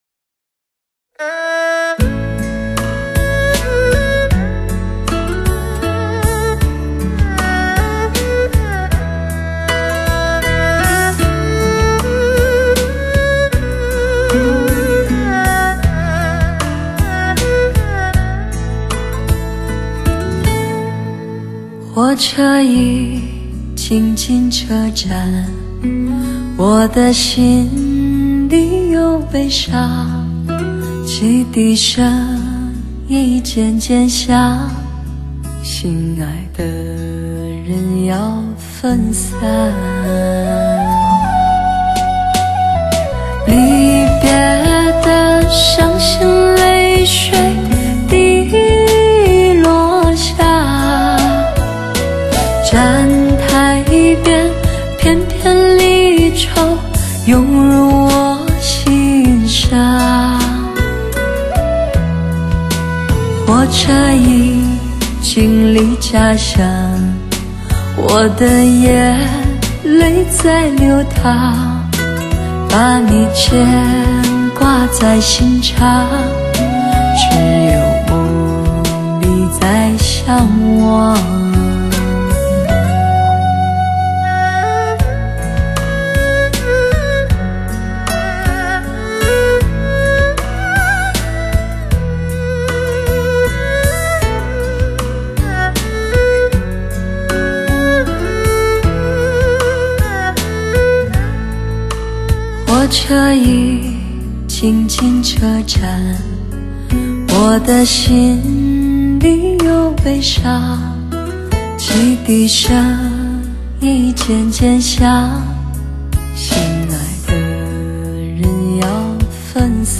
华语发烧靓声，乐迷票选榜首的最爱潮流热曲，纯净发烧的音乐质感